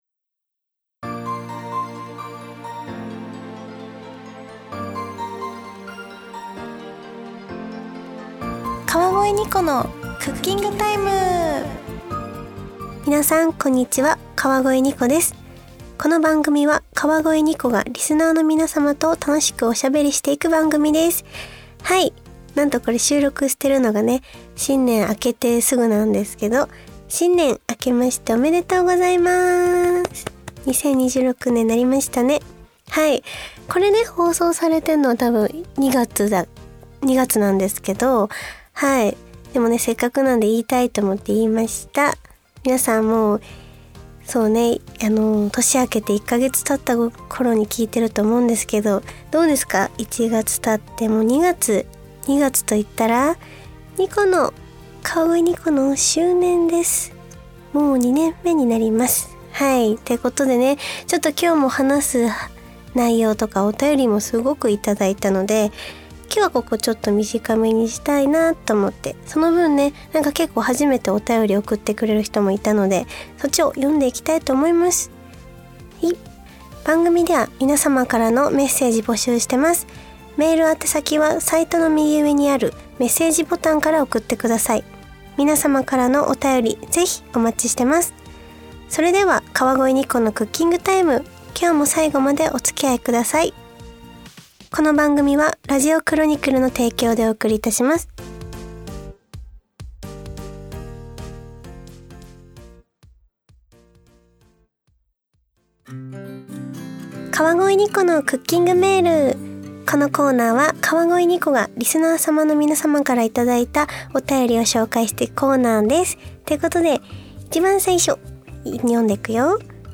今回はいつもよりちょっぴりかみかみですがそんな時もありますよね♪